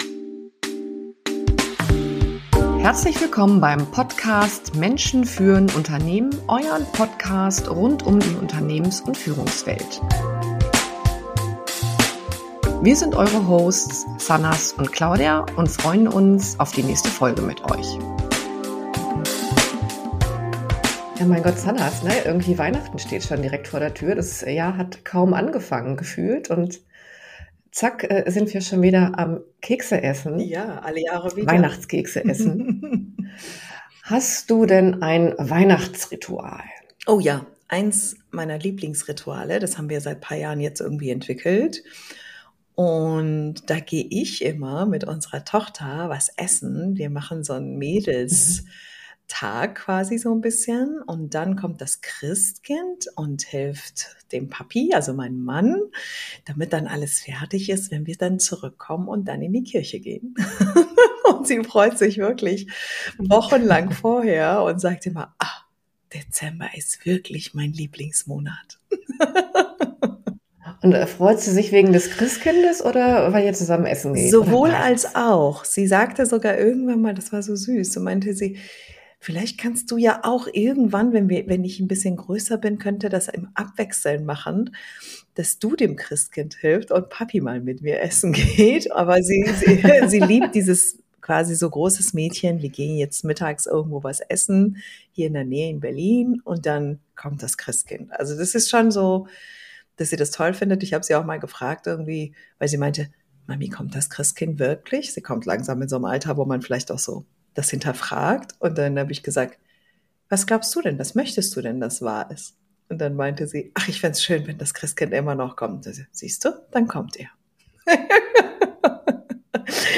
In dieser Unterhaltung reflektieren die Gesprächspartner über ihre persönlichen Weihnachtsrituale und die Bedeutung von Nächstenliebe während der Feiertage.